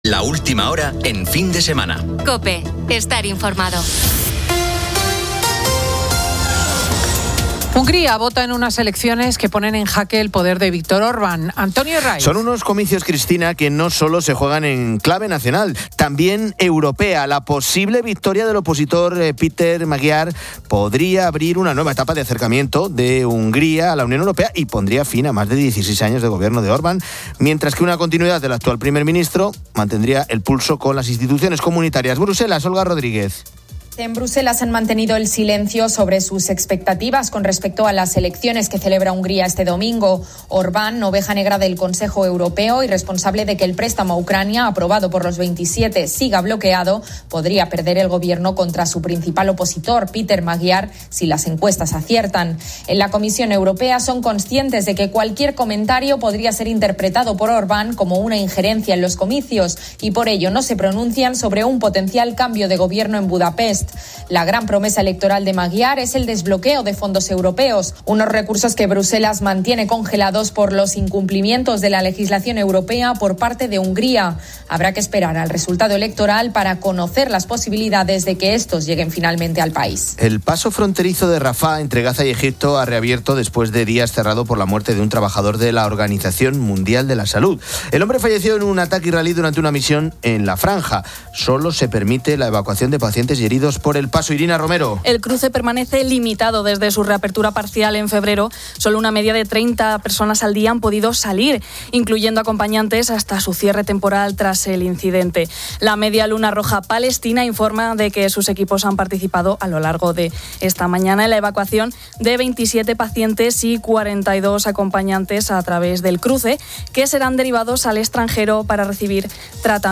Ambos nos visitan en el estudio.